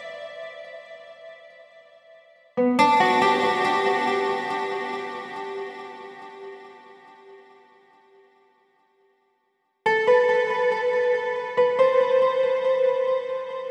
VTS1 Incast Kit 140BPM Guitar Main.wav